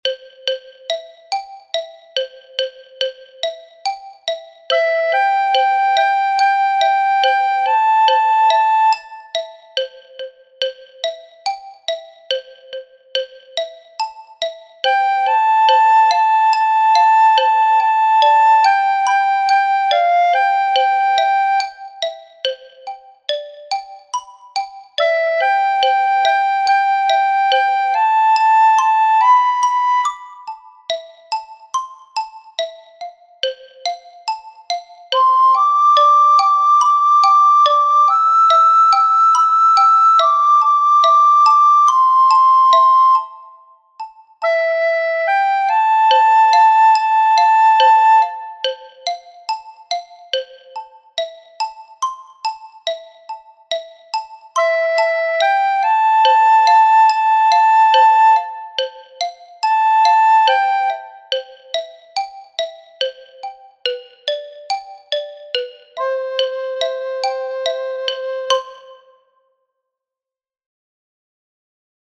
3. The music form of this piece is: A-A'-B